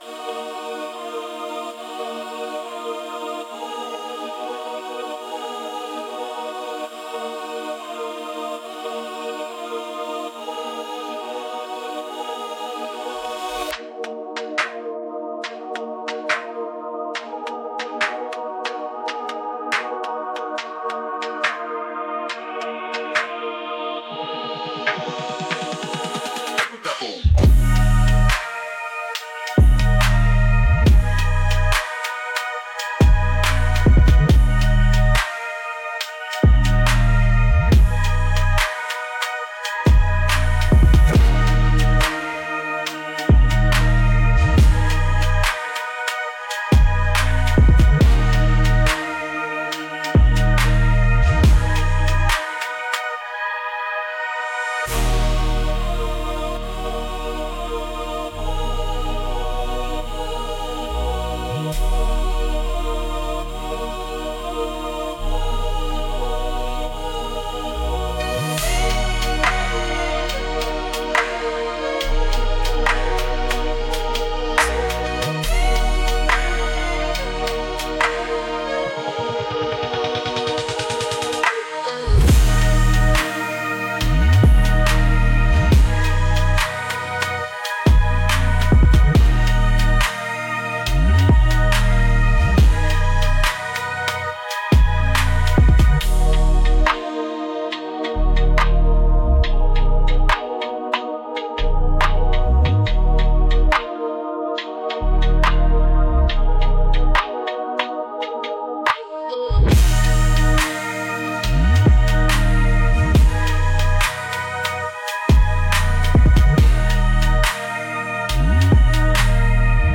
Dark - intense - 2.19 .mp3